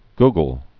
(ggəl)